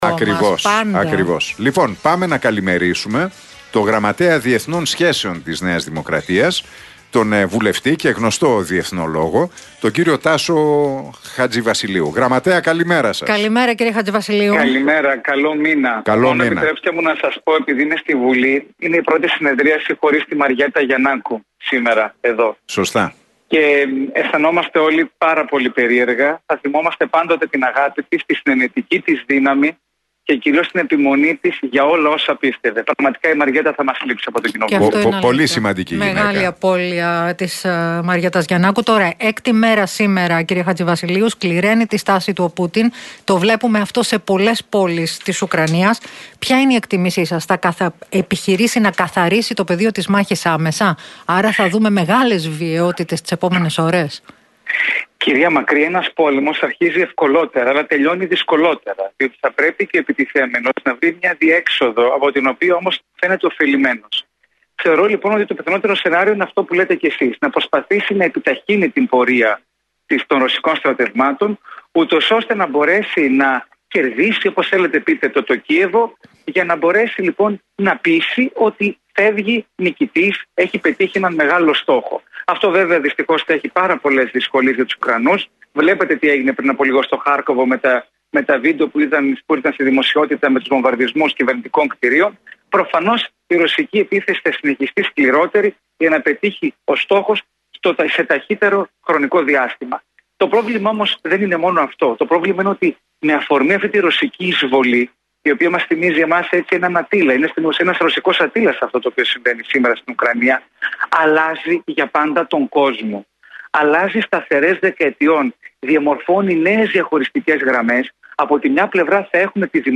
μιλώντας στον Realfm 97,8